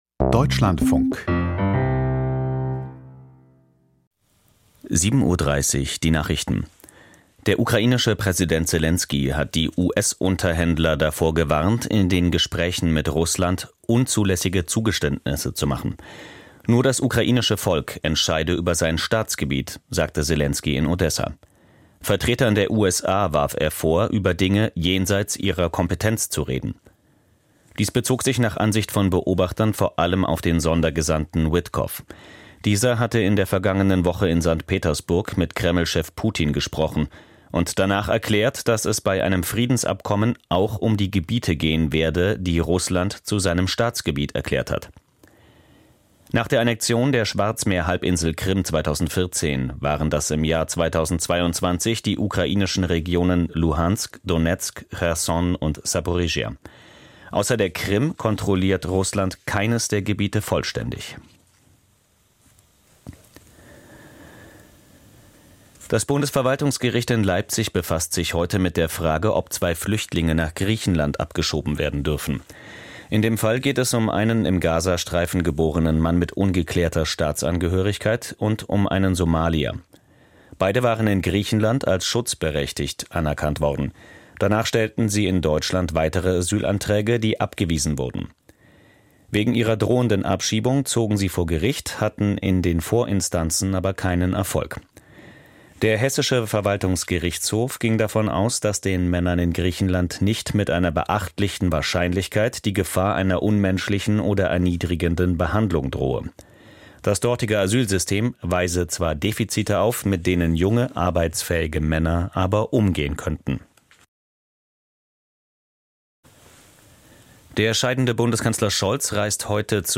Die Deutschlandfunk-Nachrichten vom 16.04.2025, 07:30 Uhr